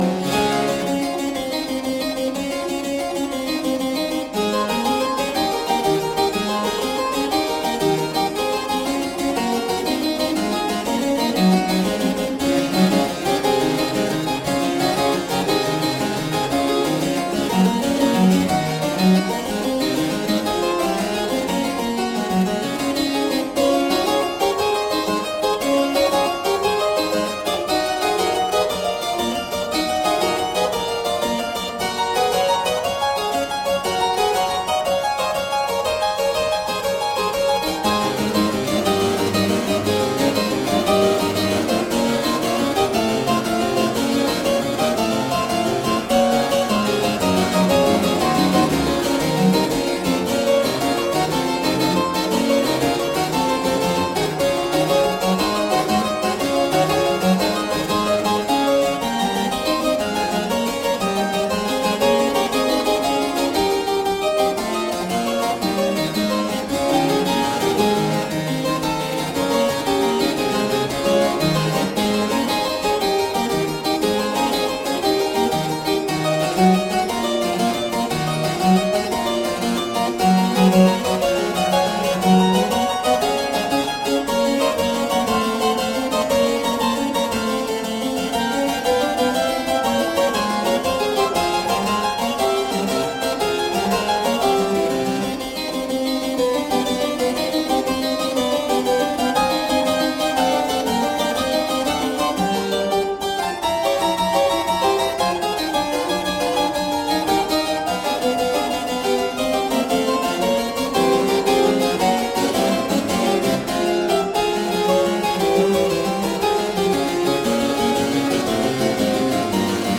Heartfelt music for harpsichord.
solo harpsichord works
Classical, Baroque, Instrumental
Harpsichord